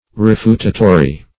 Meaning of refutatory. refutatory synonyms, pronunciation, spelling and more from Free Dictionary.
Search Result for " refutatory" : The Collaborative International Dictionary of English v.0.48: Refutatory \Re*fut"a*to*ry\ (r[-e]*f[=u]t"[.a]*t[-o]*r[y^]), a. [L. refutatorius: cf. F. r['e]futatoire.] Tending to refute; refuting.